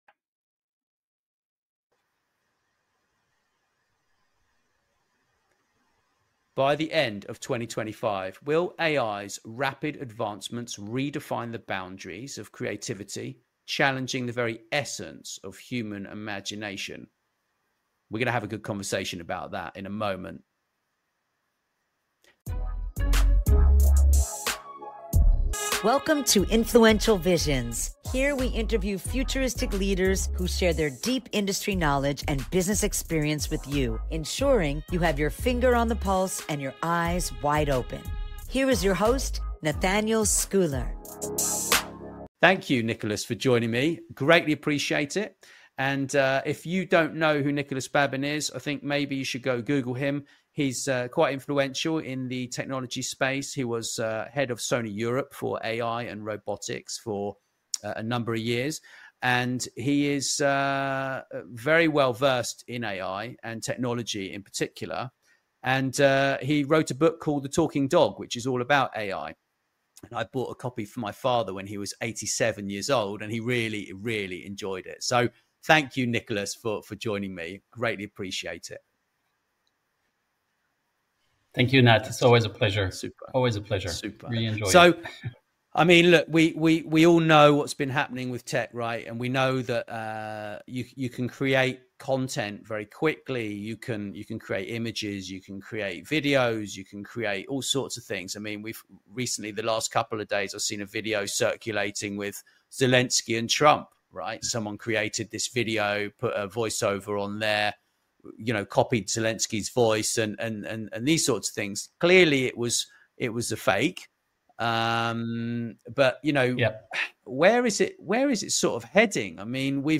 Here we interview futuristic leaders who share their deep industry knowledge and business experience with you.